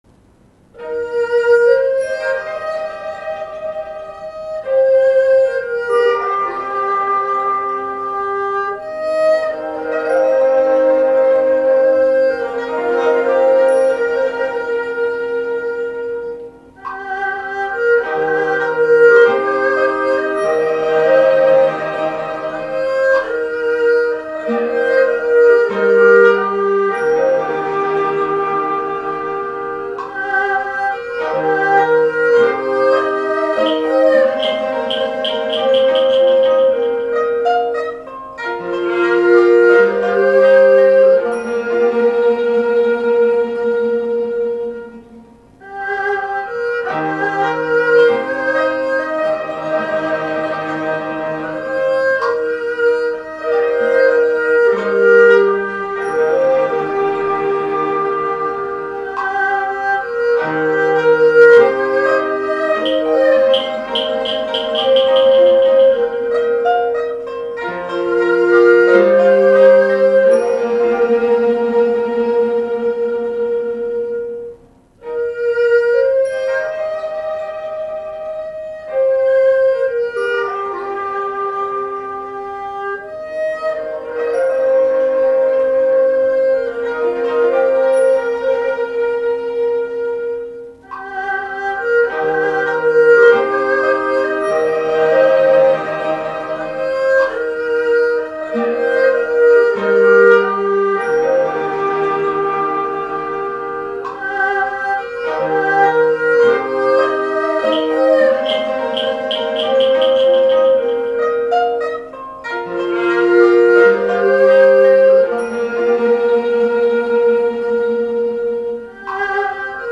CD1：傳統客家歌謠 CD2：傳統客家歌謠
伴唱><演唱>